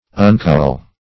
Search Result for " uncowl" : The Collaborative International Dictionary of English v.0.48: Uncowl \Un*cowl"\, v. t. [1st pref. un- + cowl.] To divest or deprive of a cowl.